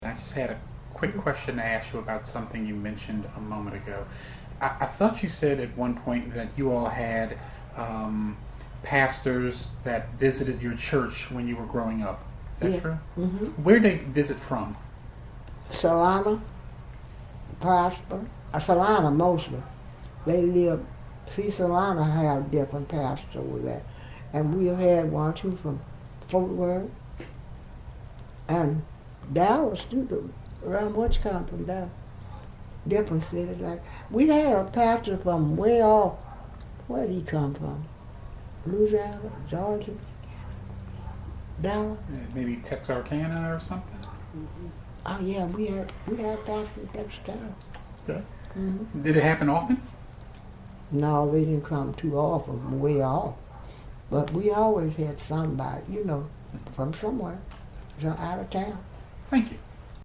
oral interview